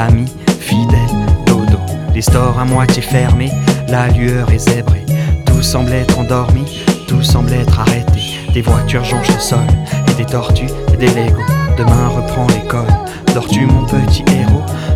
studio du Flon, Lausanne
feelin’ studio, Prilly